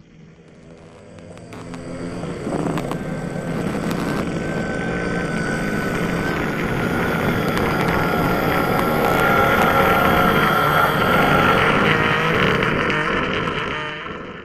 Minecraft.Client / Windows64Media / Sound / Minecraft / mob / endermen / stare.ogg